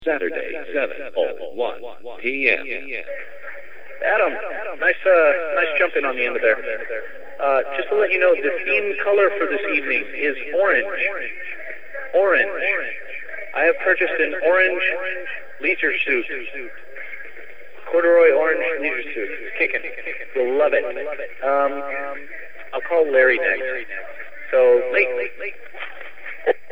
Playing all kinds of grooves and having a crazy time.